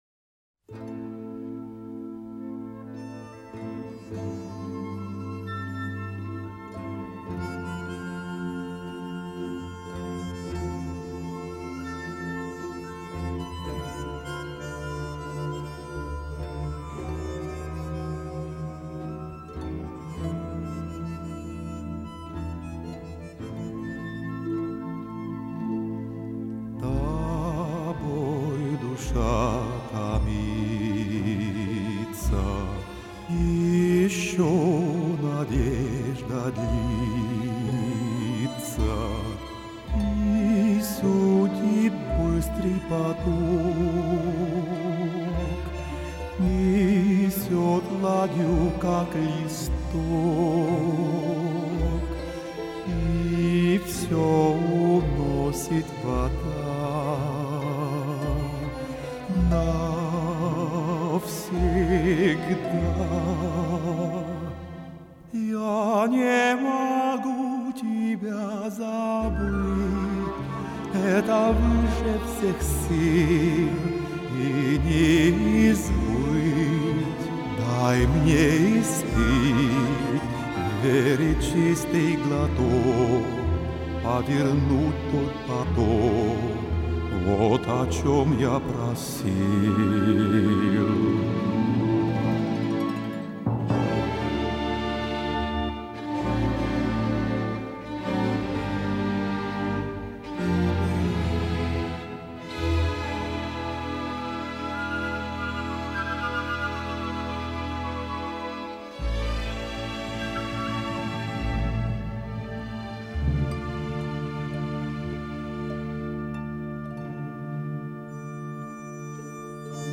Христианская музыка